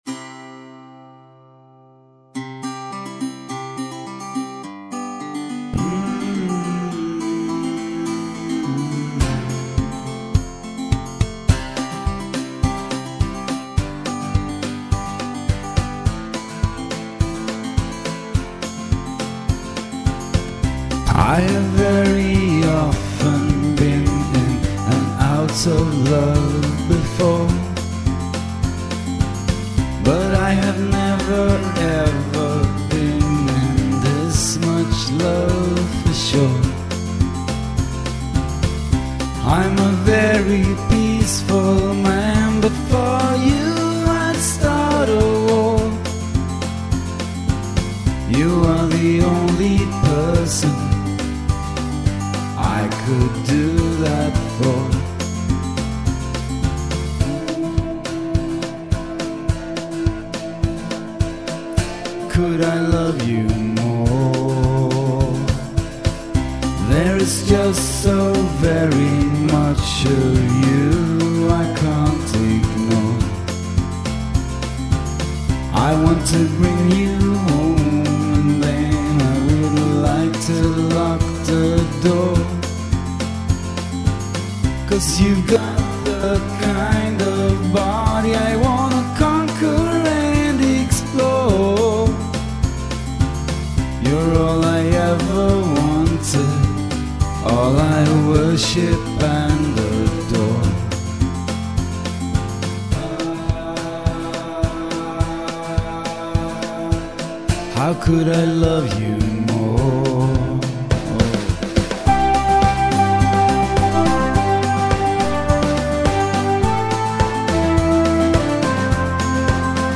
(Pop)